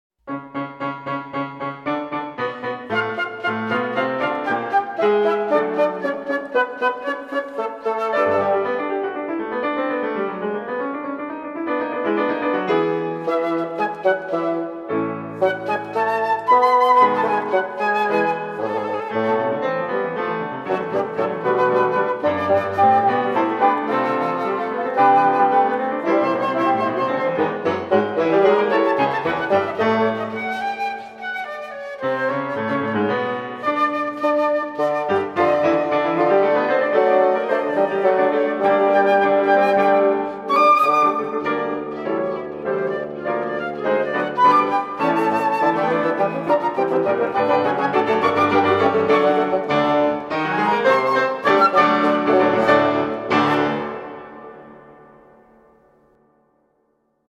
Suite for Flute, Bassoon and Piano: 4th movement